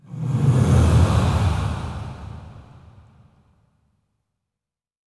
SIGHS 3   -R.wav